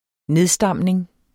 Udtale [ -ˌsdɑmneŋ ]